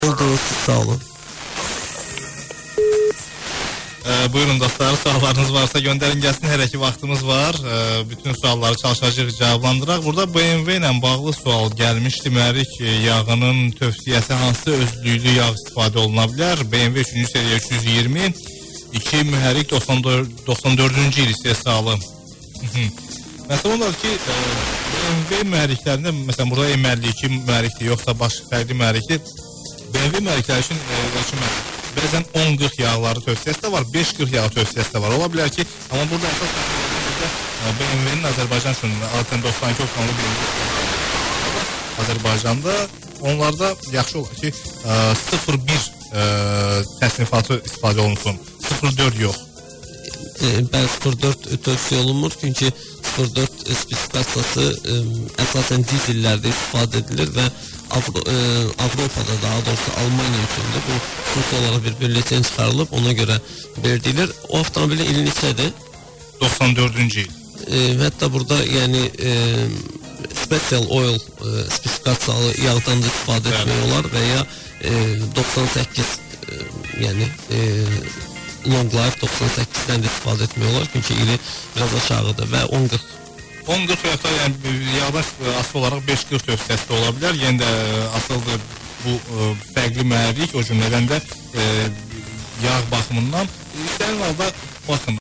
88,0 MHz Azərbaycan Radio (+ egy kis baki Bakuból - jó mély levegőt vett a bemondó nő, majd +10dB-lel a "spot")